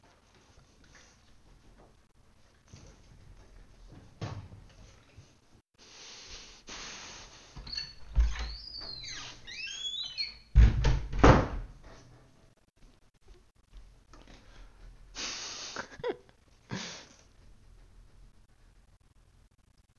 backnoise (3).wav